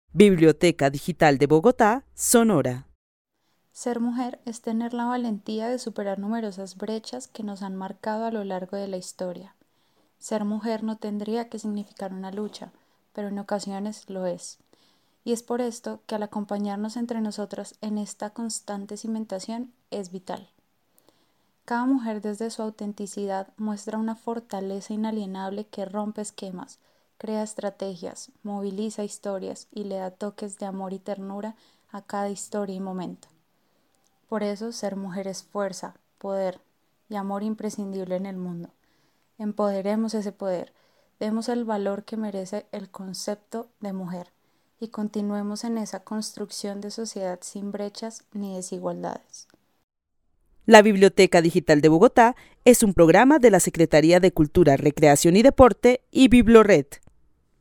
Narración oral sobre lo que significa ser mujer. La narradora es una mujer de Bogotá que destaca la valentía que han tenido las mujeres para superar brechas históricamente. En su relato resalta la importancia de darle el valor que se merece al concepto de mujer, para así construir una sociedad sin desigualdades. El testimonio fue recolectado en el marco del Laboratorio de co-creación Postales sonoras: mujeres escuchando mujeres de la línea de Cultura Digital e Innovación de la Red de Distrital de Bibliotecas Públicas - BibloRed.